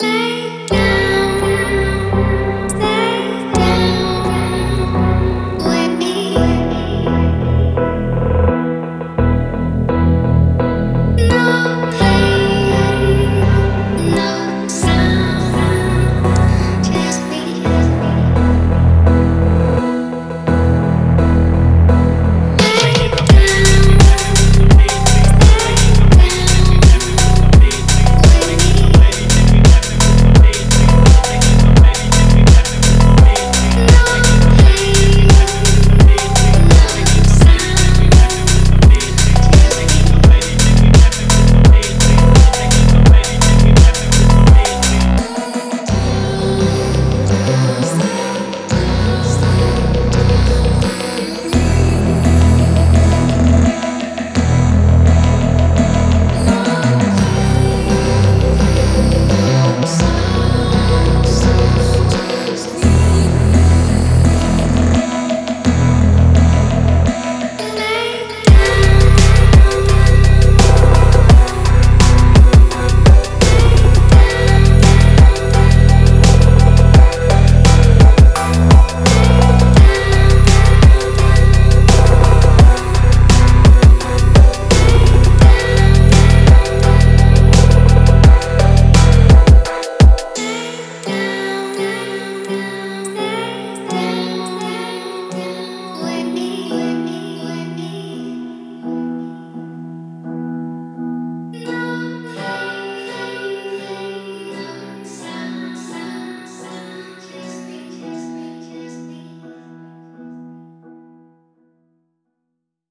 Фонк музыка
Phonk